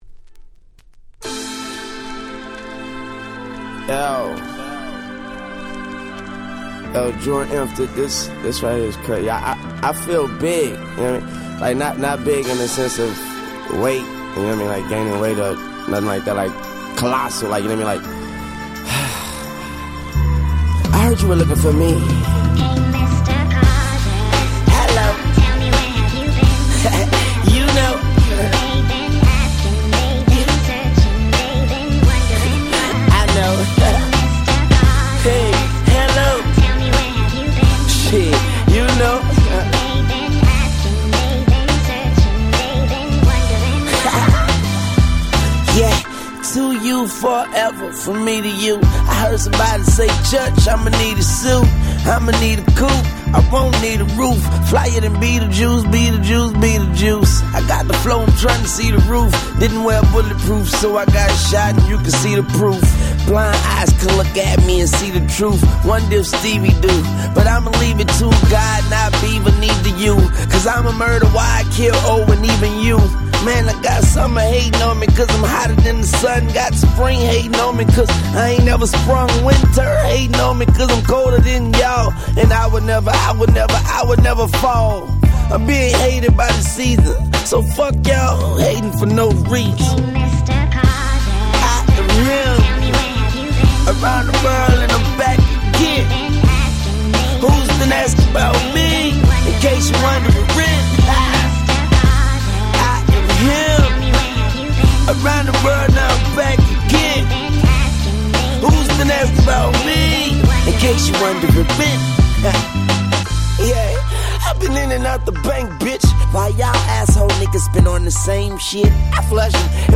08' Smash Hit Hip Hop !!